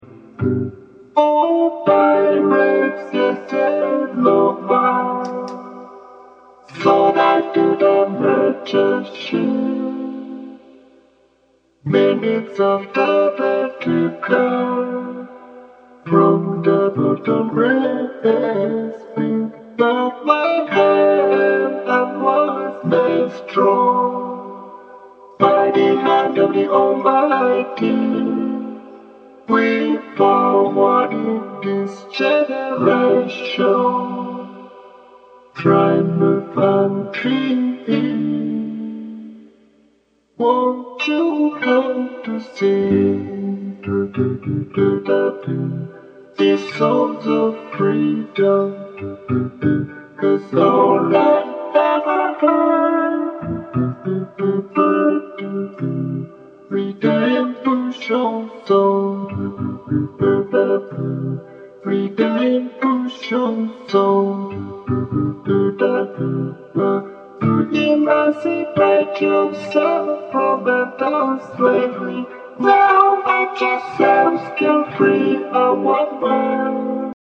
温もりが伝わるギターから幕開け